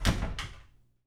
DOOR_old wood close198.AIF